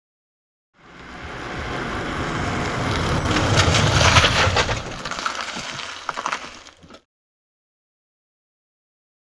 Automobile; In / Stop / Off; Audi 80l Up And Stop With Engine Stall And Curbside Grit.